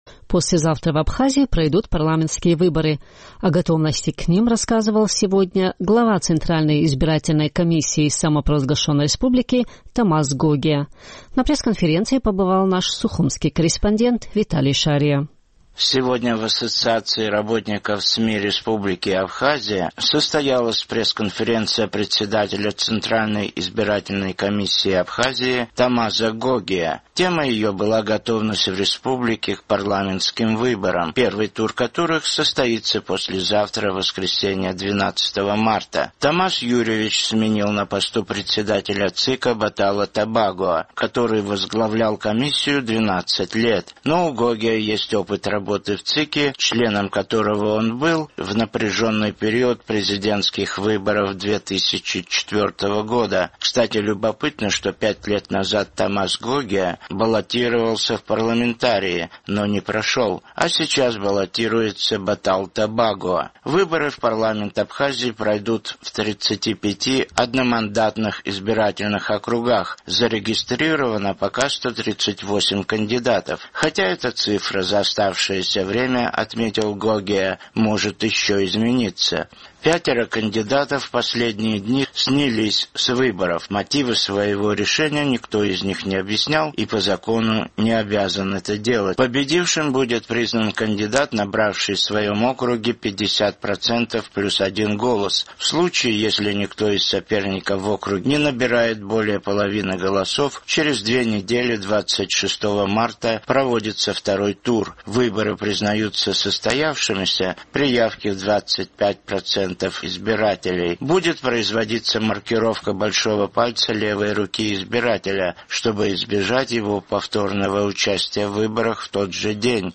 Сегодня в Ассоциации работников СМИ Республики Абхазия состоялась пресс-конференция председателя абхазского ЦИКа Тамаза Гогия. Темой ее была готовность в республике к парламентским выборам.